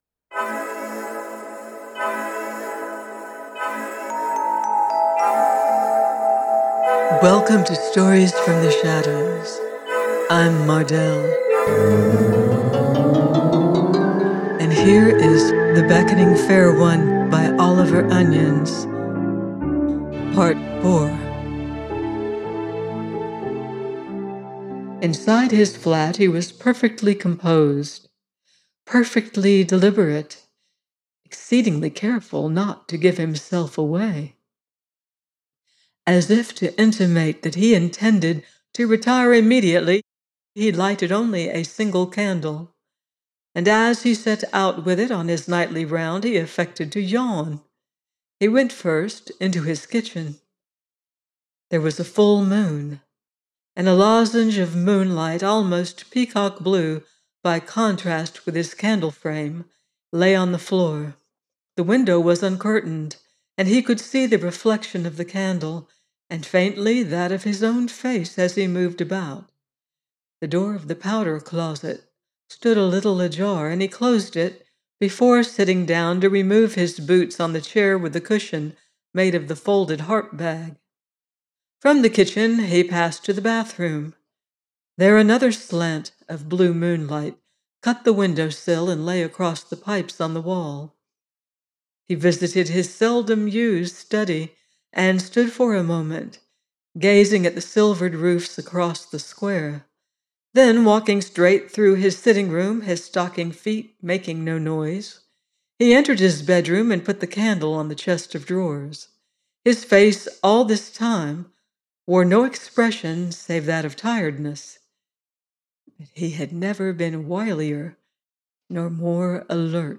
The Beckoning Fair One – Oliver Onions - audiobook